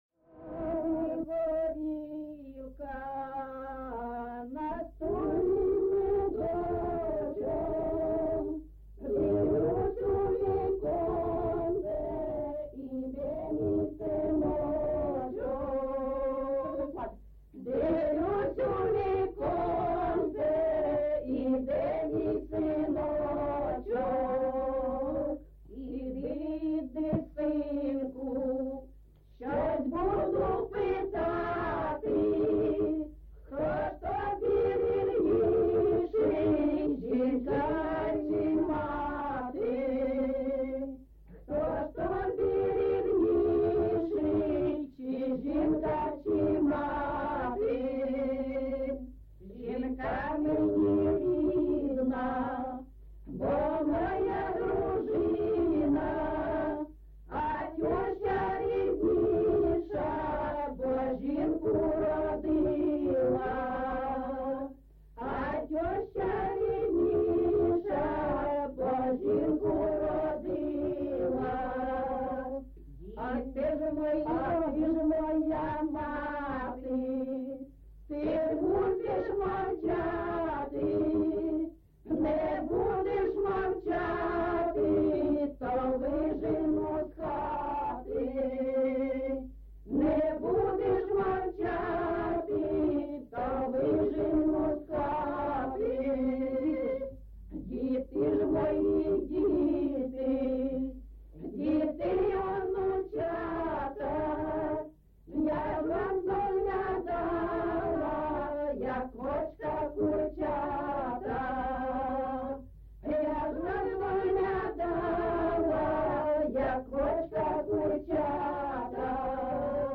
GenrePersonal and Family Life, Neo-Traditional Folk
Recording locationHalytsynivka, Pokrovskyi district, Donetsk obl., Ukraine, Sloboda Ukraine